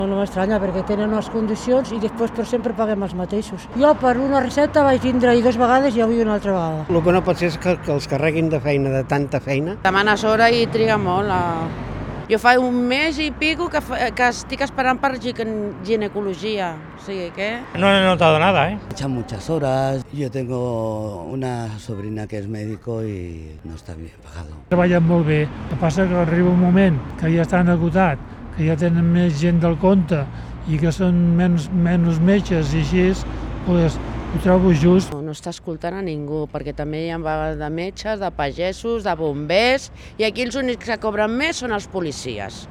Els usuaris que avui han passat per l’ambulatori de Calella asseguren que comprenen les seves queixes.